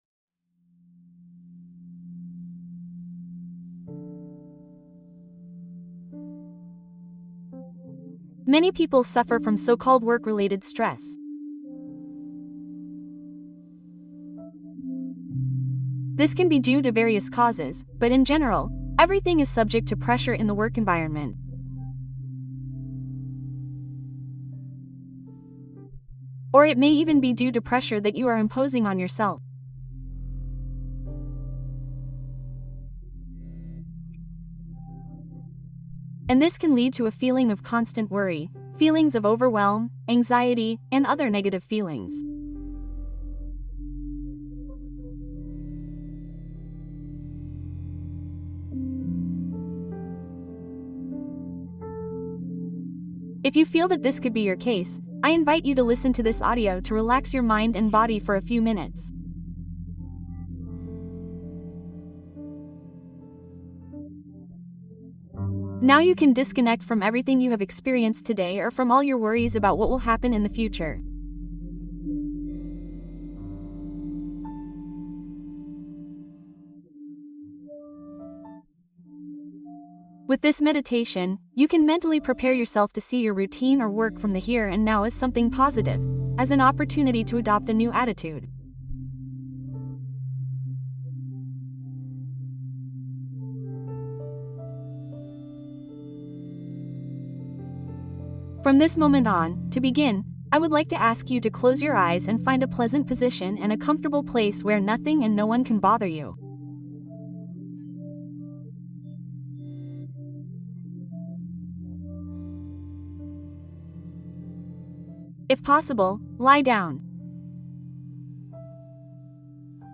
Meditación para estrés laboral y calma emocional